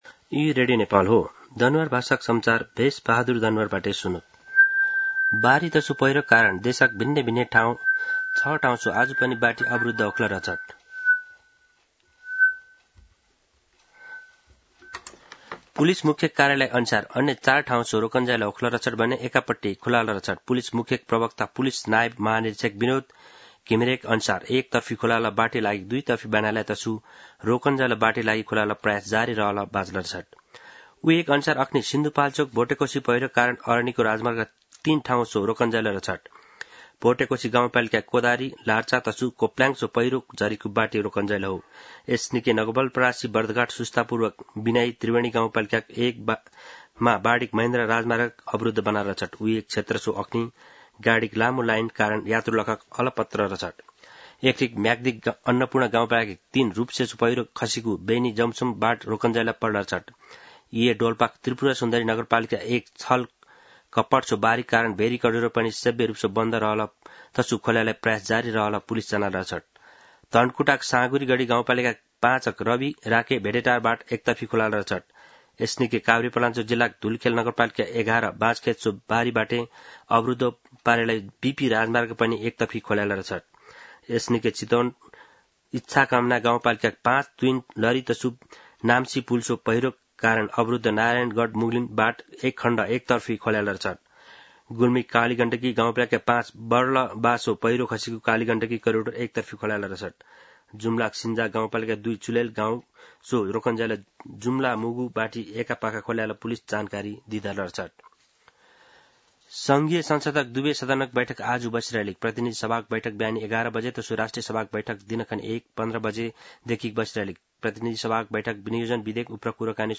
दनुवार भाषामा समाचार : ५ असार , २०८२
Danuwar-News-1.mp3